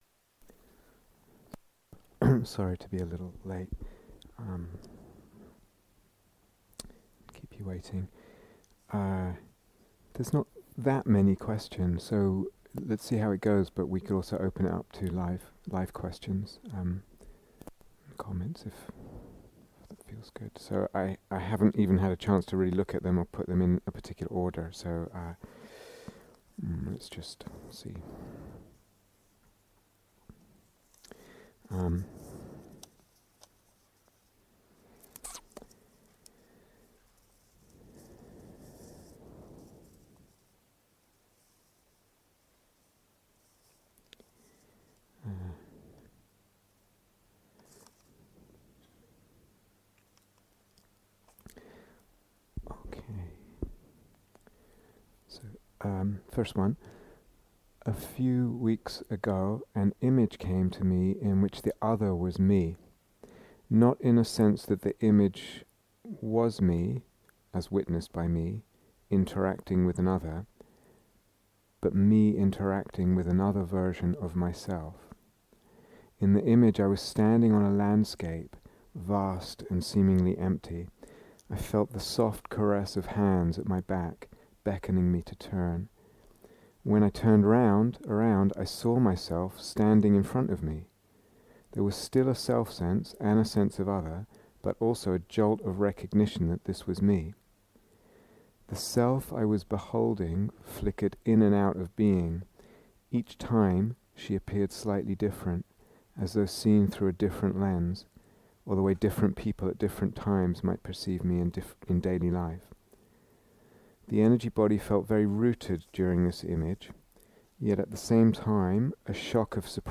On Practising with Images (Q & A)